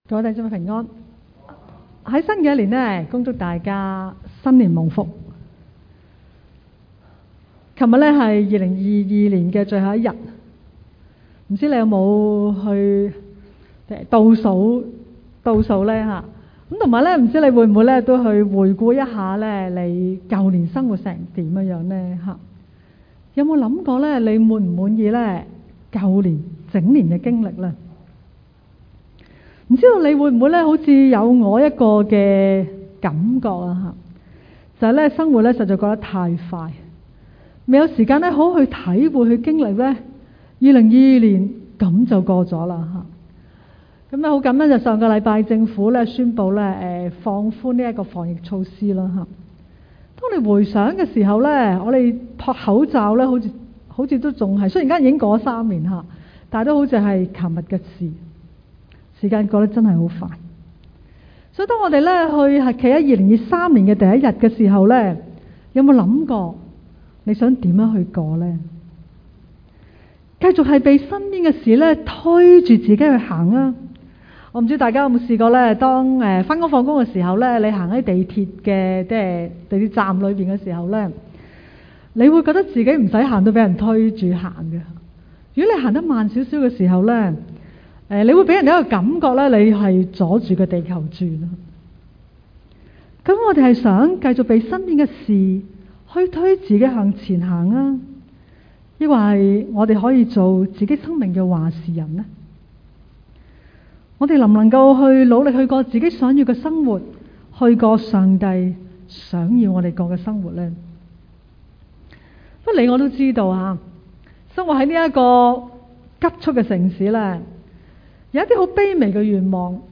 場所：新年崇拜